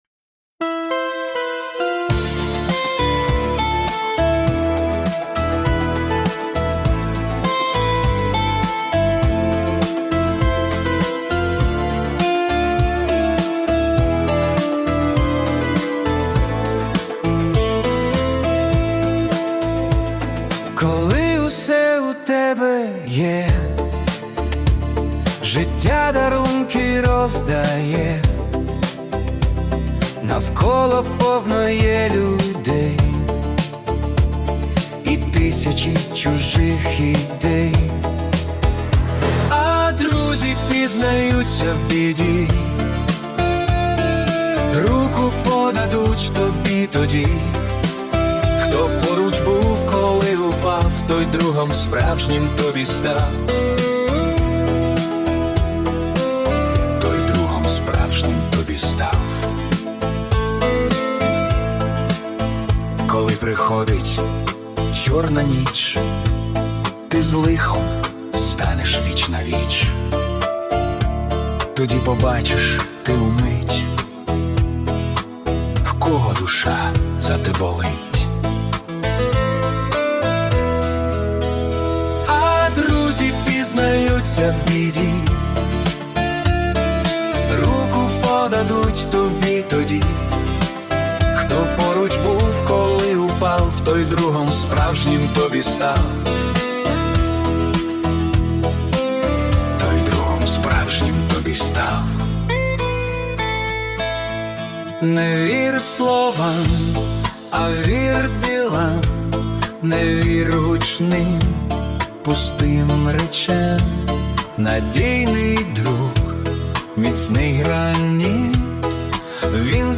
це глибока та щира музична балада